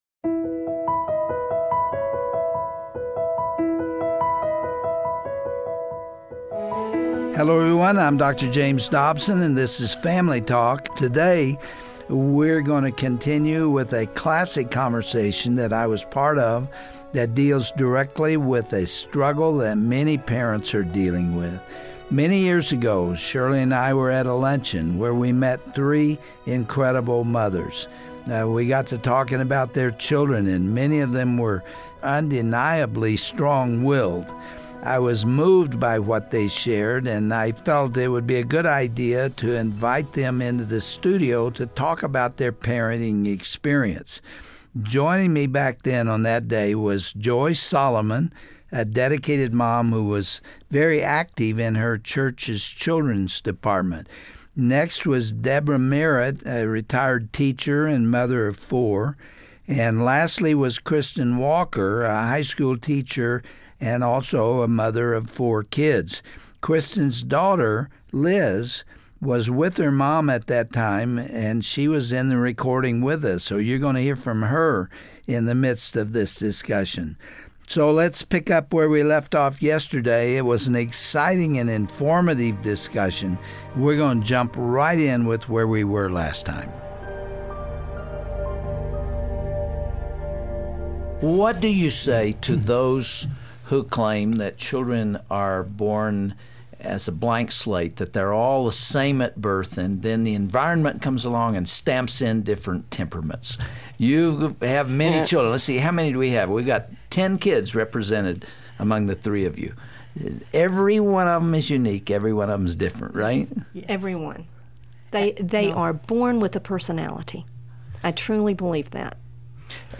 On today's edition of Family Talk, Dr. James Dobson talks with three mothers who candidly share the frustrations and challenges of parenting their "tough as nails" children.